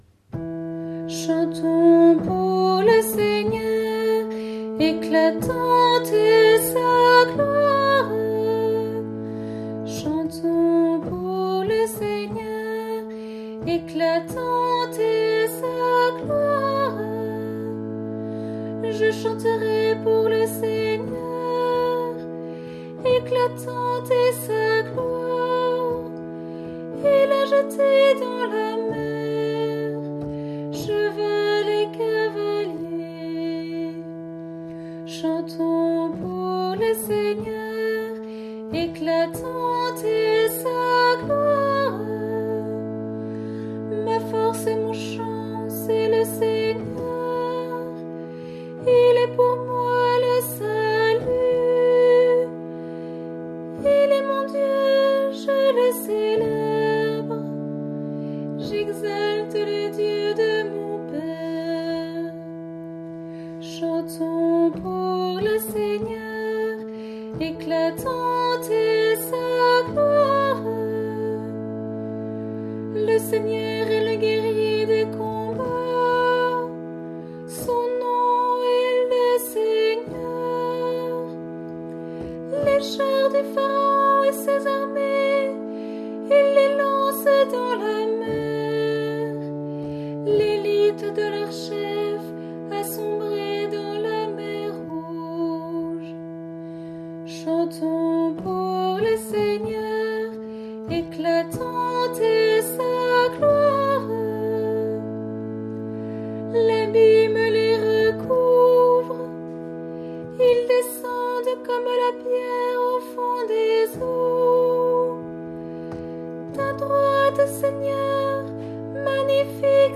Cantique pour la Vigile Pascale